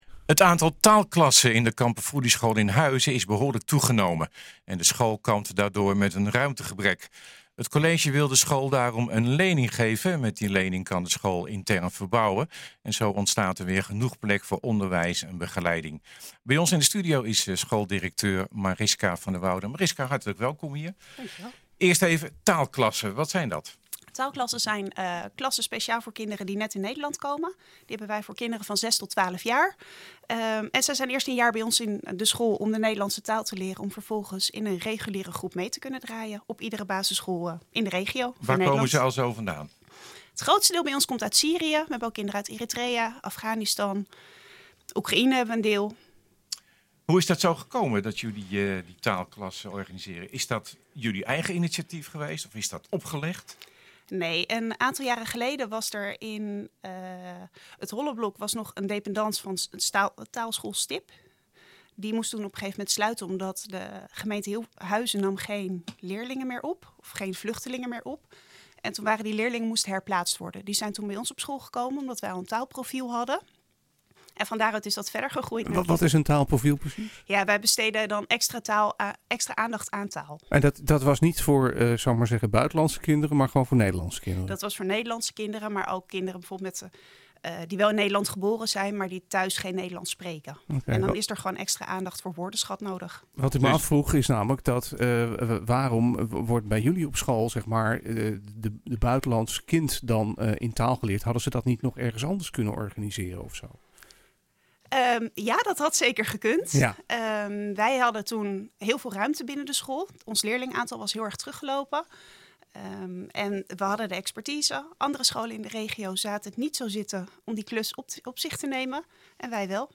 NHGooi is de streekomroep voor Gooi & Vechtstreek.